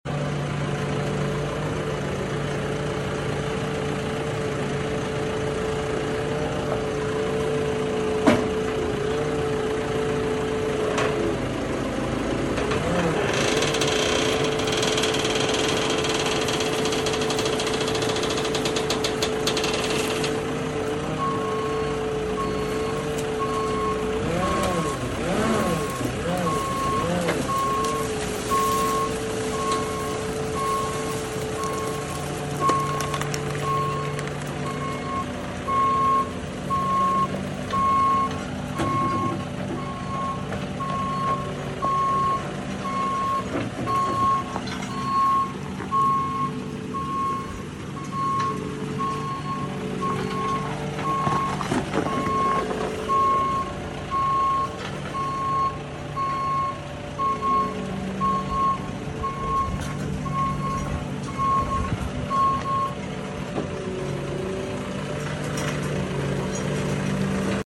Can you guess what tractor sound effects free download